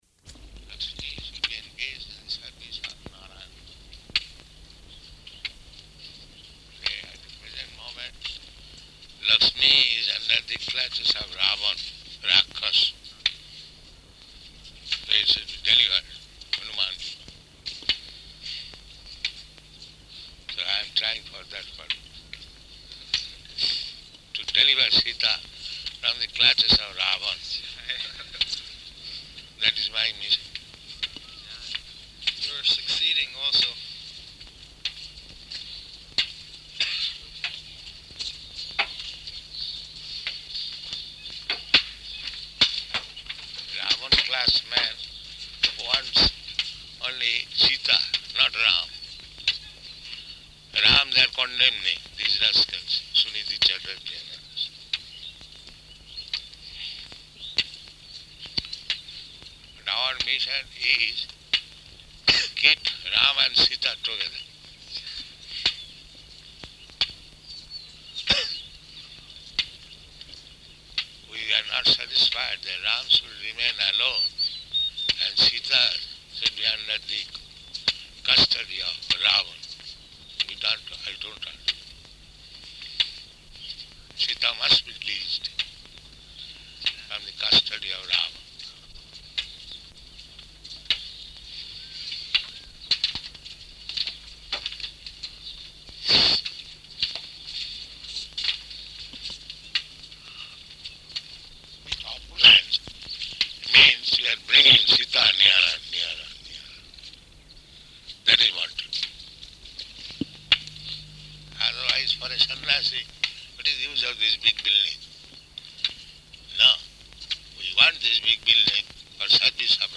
Morning Walk [partially recorded]
Type: Walk
Location: Māyāpur
[low level audio]